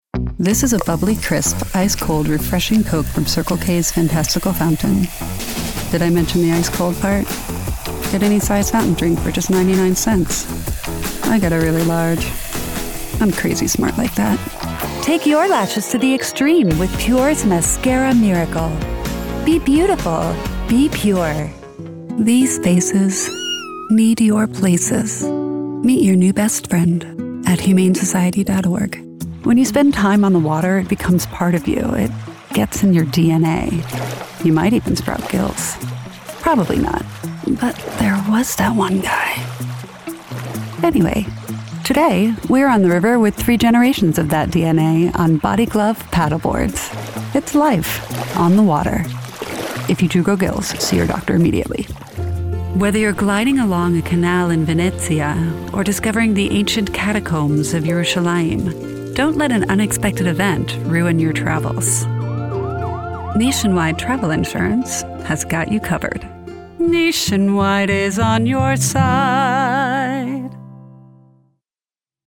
My voice has been described as warm, intelligent, & effortlessly cool - perfect for projects that demand professionalism & natural delivery. I'm known for pairing approachable expertise with a comforting, friendly & competent tone.
Commercial Demo